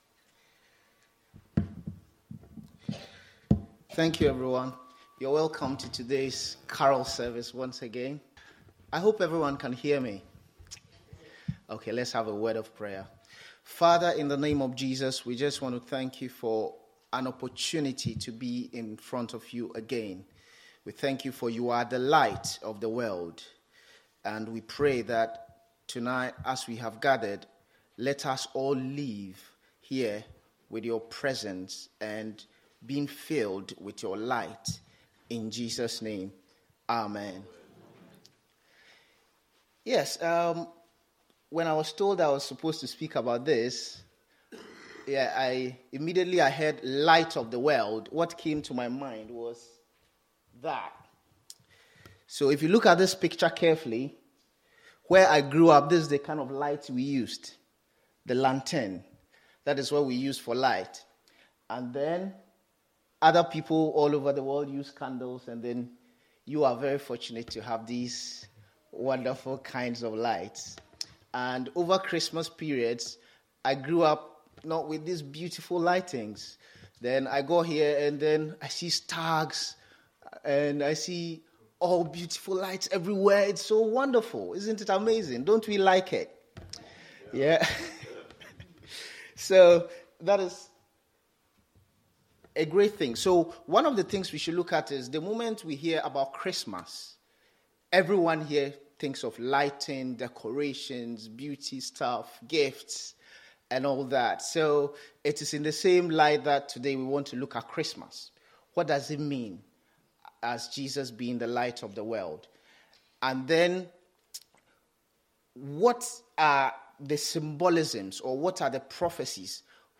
Jesus-The-Light-of-the-World-Carols-evening.mp3